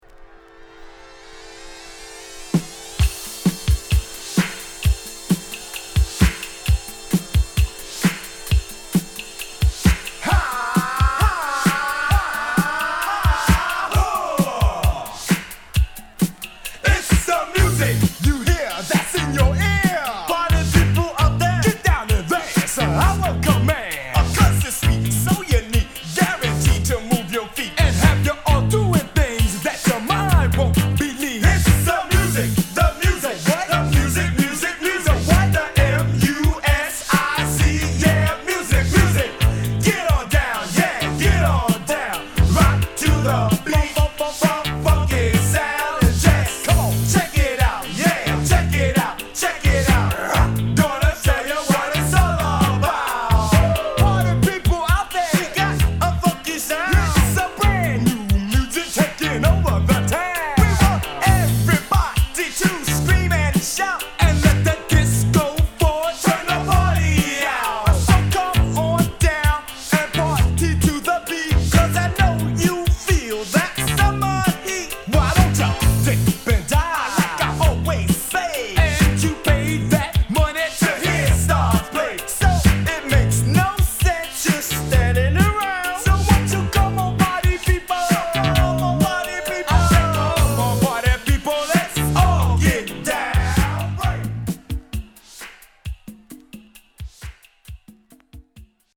NYはハーレム出身の5 人組オールド・スクールラップ！